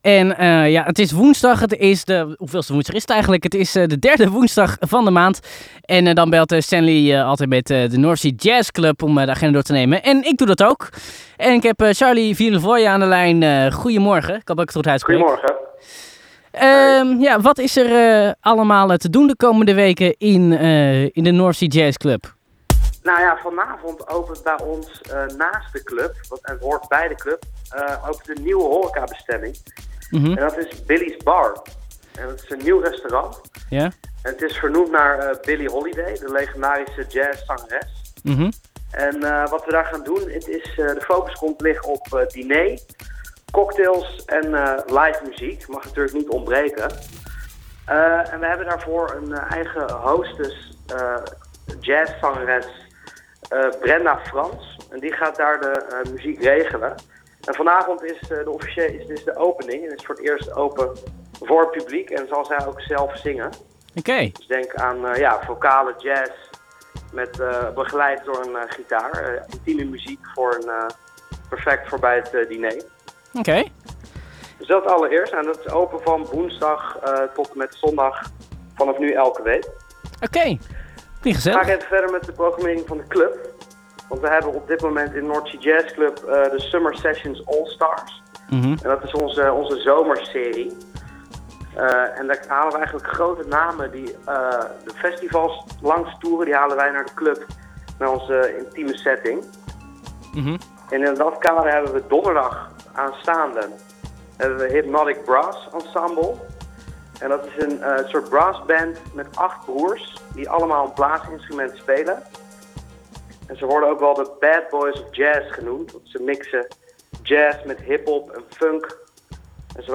Elke maand belt Amsterdam Light met de North Sea Jazz club om de agenda voor de komende weken door te nemen.